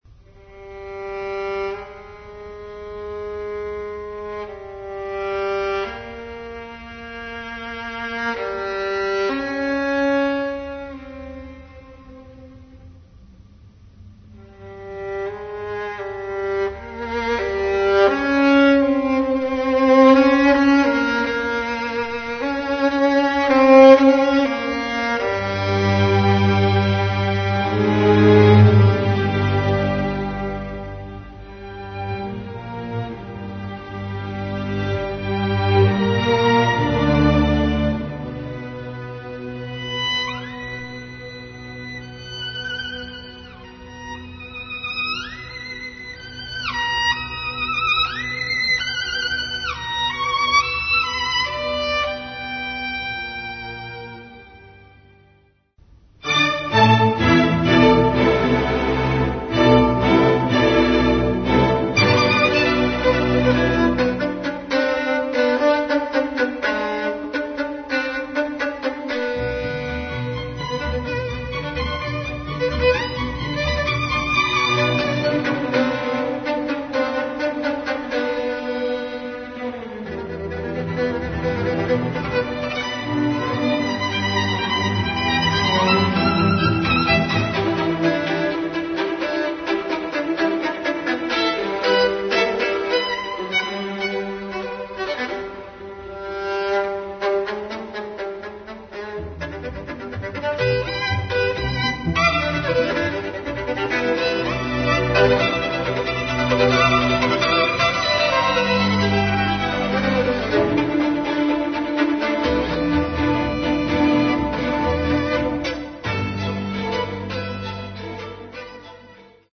na skrzypce i orkiestrę smyczkową
obsada: vn solo-archi